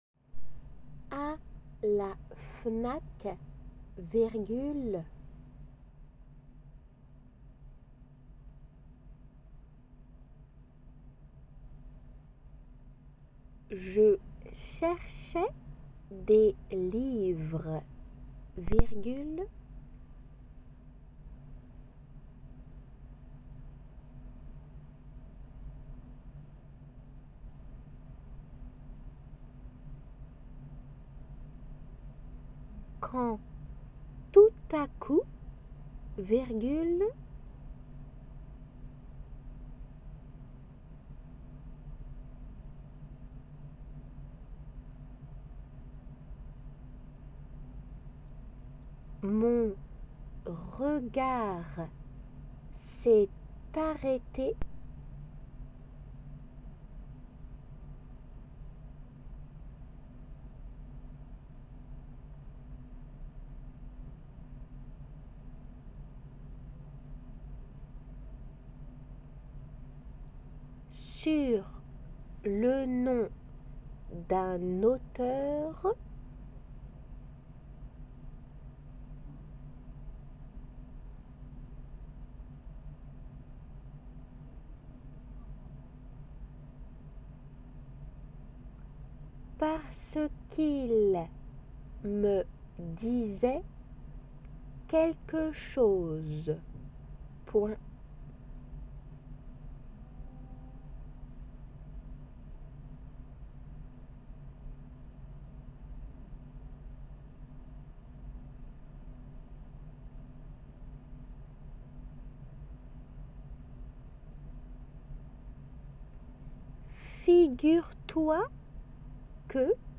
練習用　過去問とは傾向が異なり難易度の高い文章のデイクテです。
C  女性
Ｒ 男性